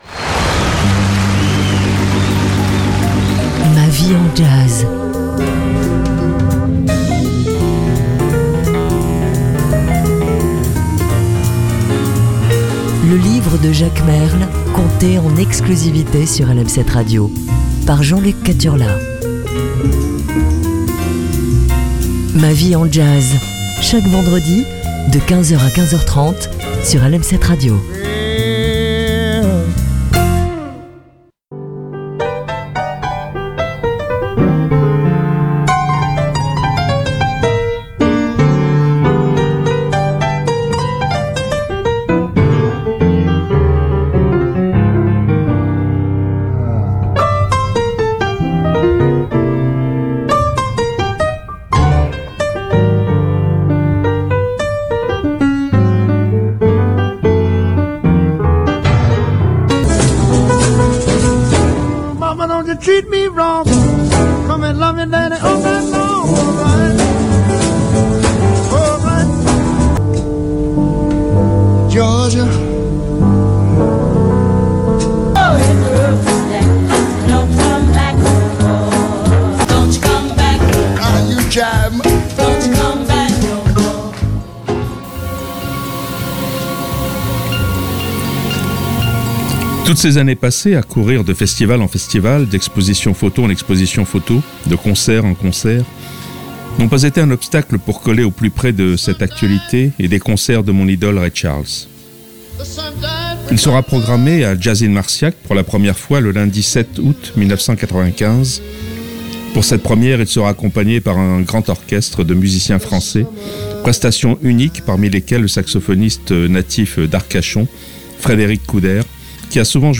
une demi heure jazz & littérature